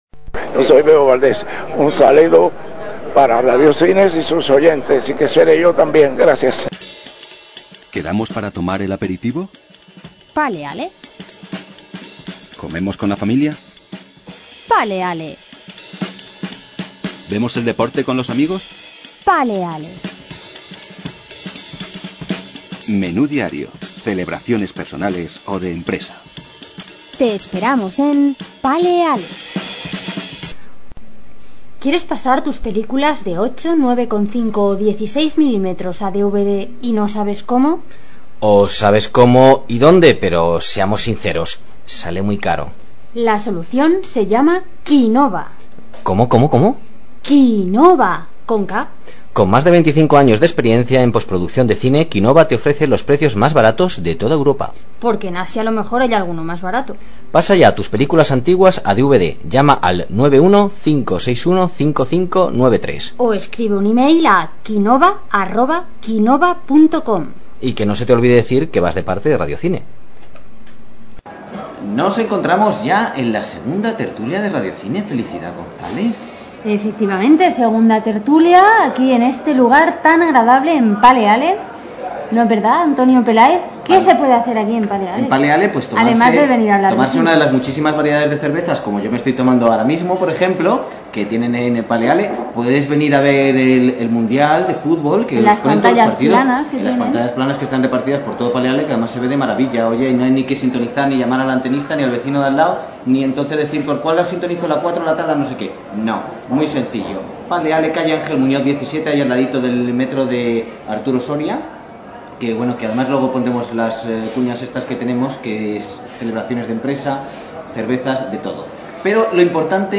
Tertulia sobre el futuro del cine